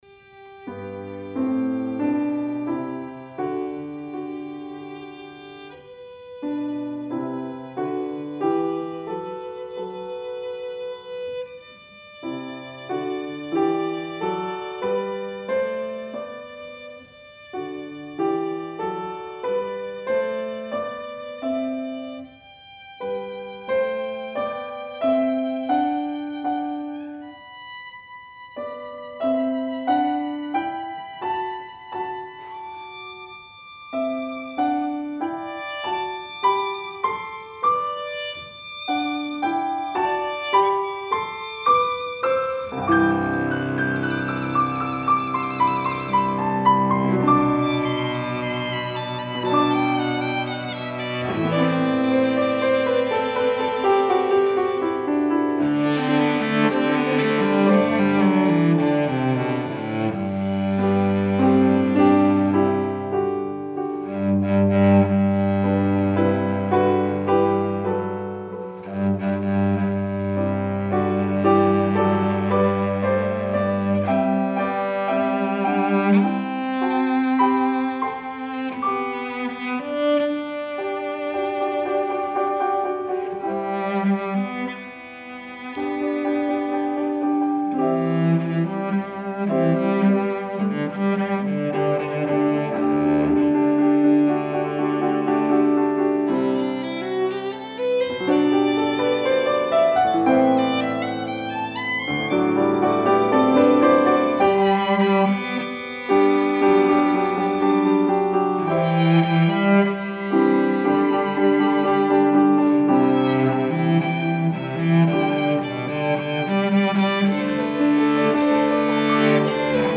for Piano Trio (2015)
This is gentle music -- both flowing and calm.